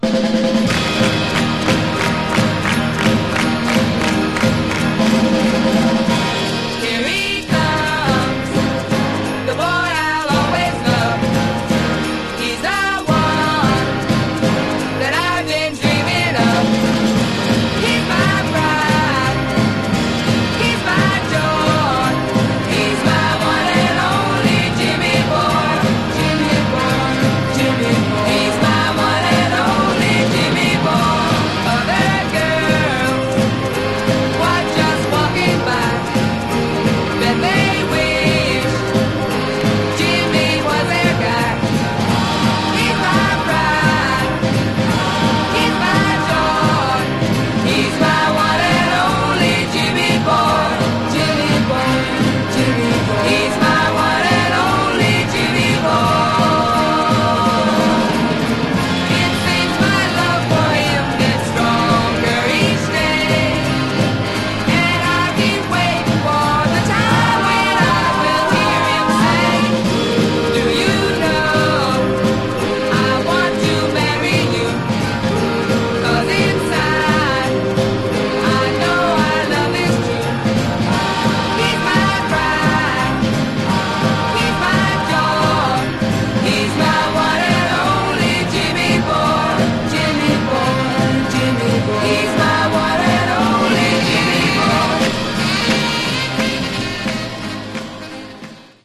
Genre: Girl Group
This fast-paced Girl Group rocker